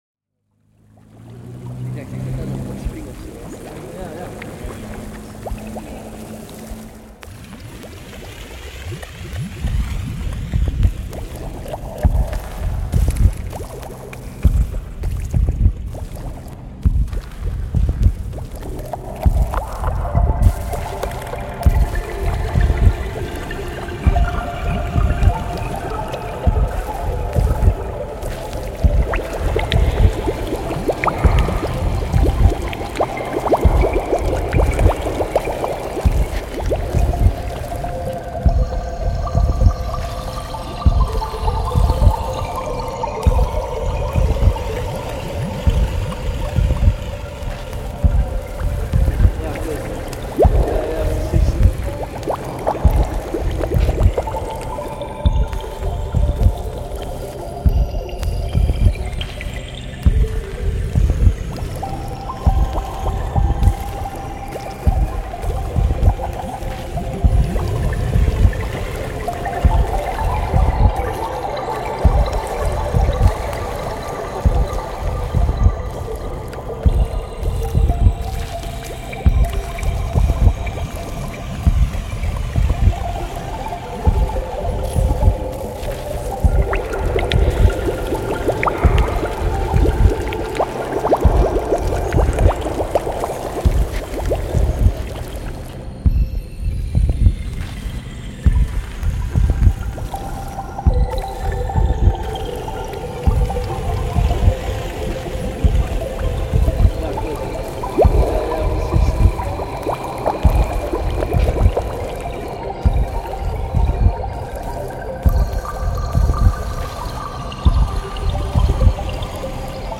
Reimagined sound of bubbling water spring on the Bolivian salt flats